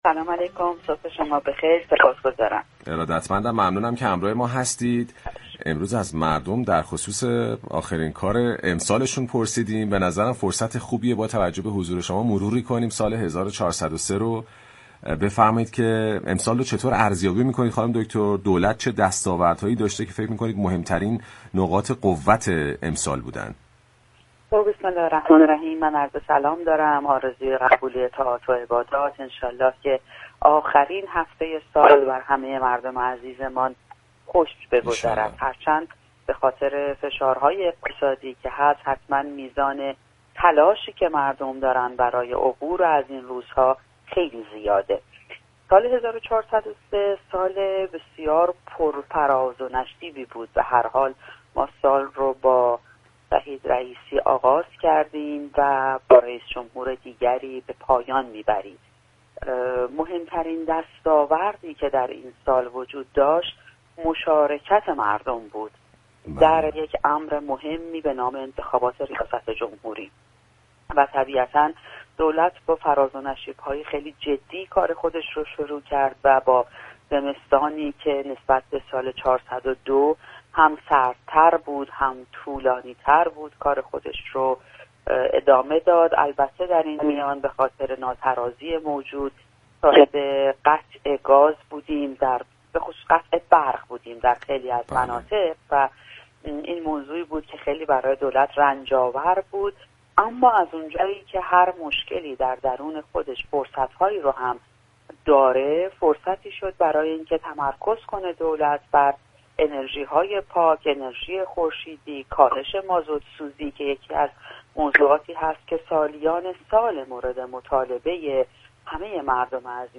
فاطمه مهاجرانی سخنگوی دولت در گفت و گو با «بام تهران»